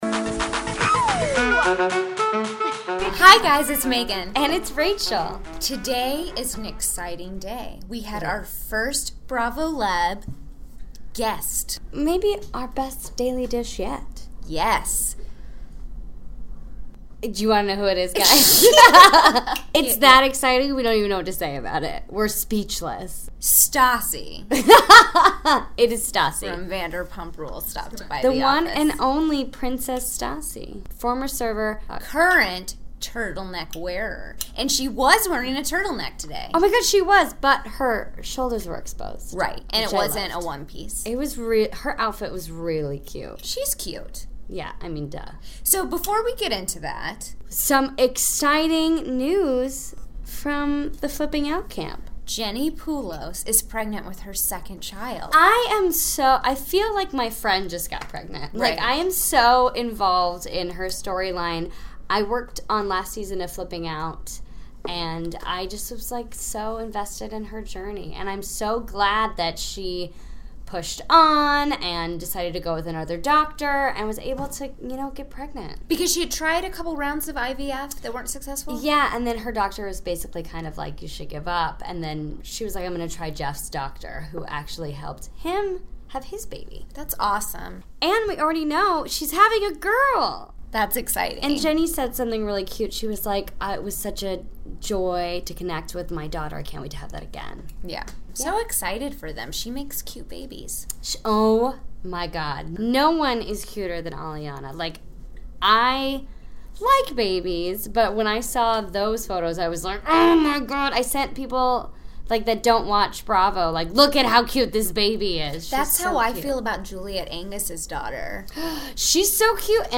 Thursday, January 12, 2017 - From Bravo HQ in New York City, we're talking to Vanderpump Rules' Stassi Schroeder about villains, her podcast, and Scheana and Mike Shay's divorce. Plus - the Flipping Out family gets a new addition.